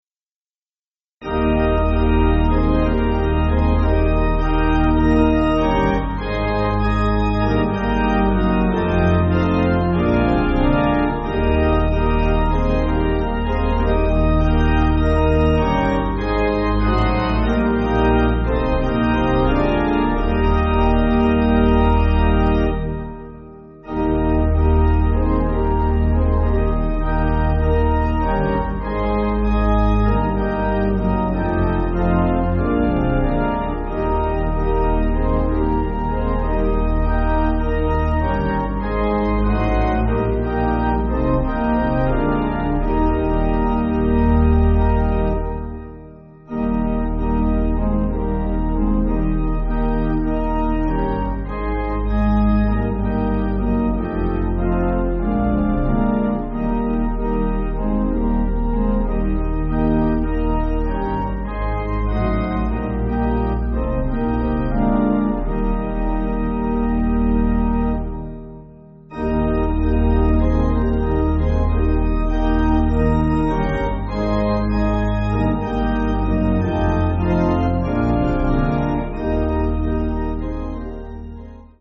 (CM)   5/Eb